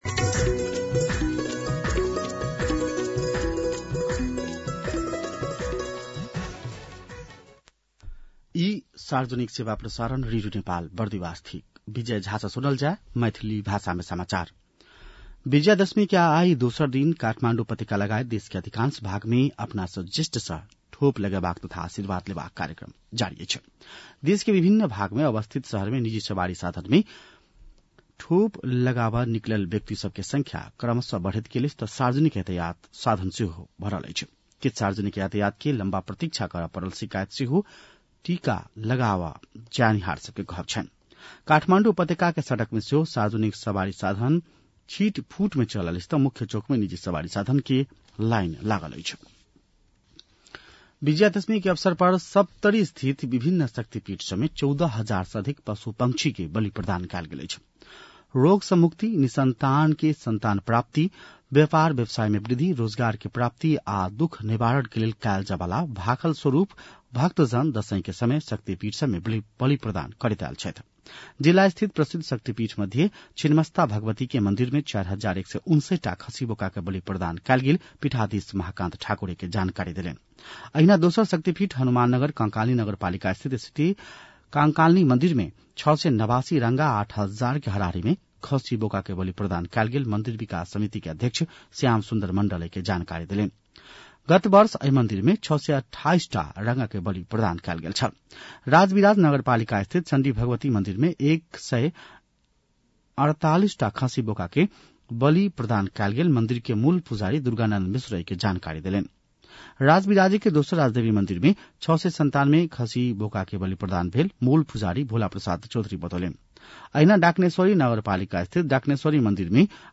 मैथिली भाषामा समाचार : १७ असोज , २०८२